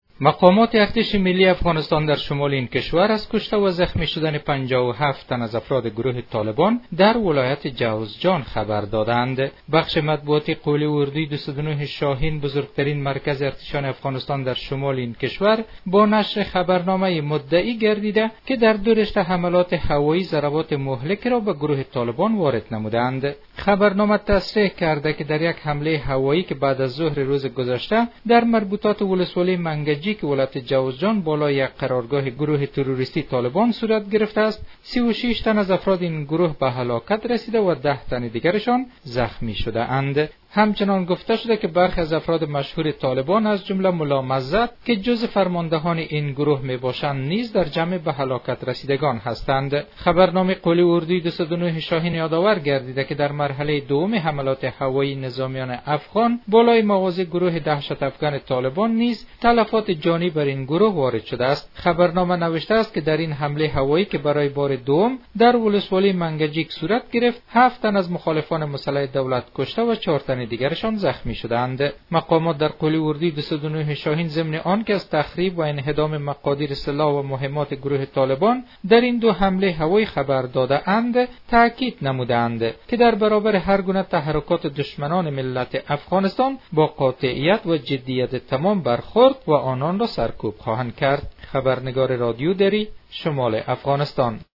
به گزارش خبرنگار رادیودری، بخش مطبوعاتی قول اردوی 209 شاهین در شمال افغانستان با نشر خبرنامه ای مدعی گردیده که در دو رشته حملات هوایی ضربات مهلکی را به گروه طالبان وارد نموده اند.